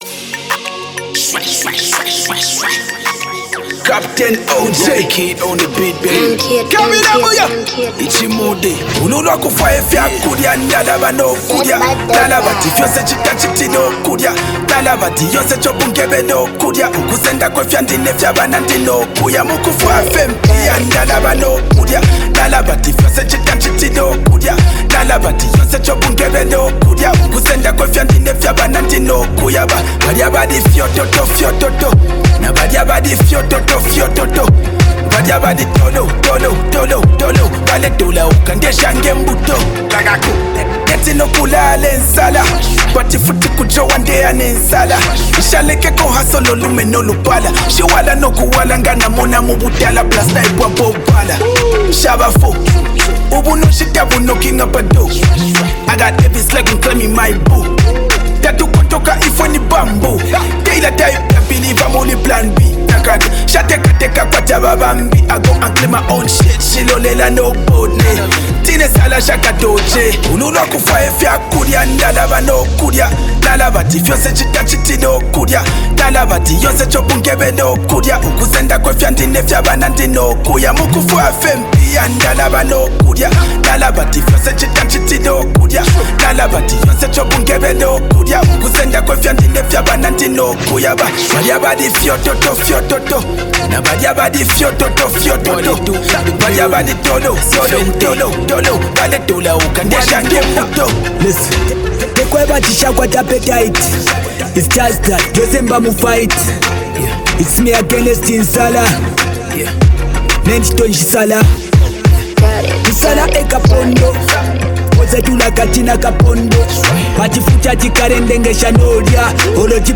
Genre: Zambian Music